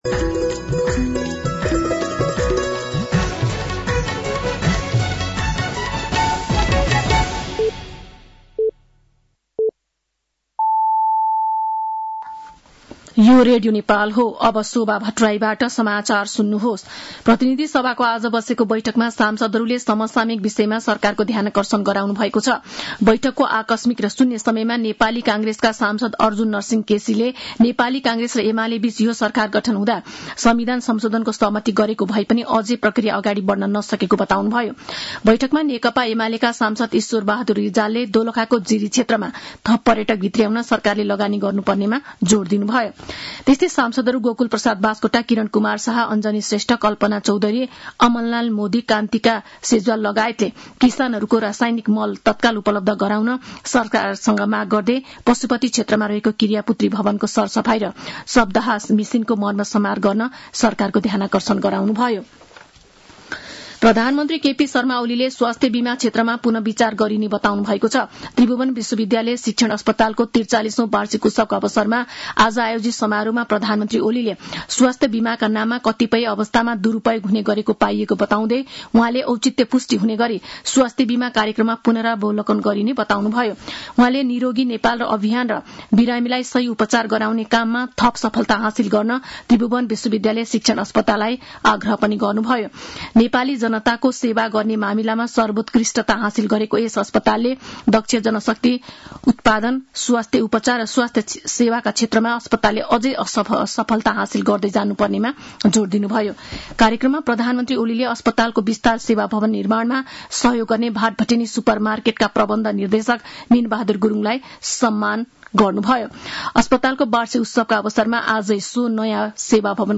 साँझ ५ बजेको नेपाली समाचार : ३० असार , २०८२
5-pm-nepali-news.mp3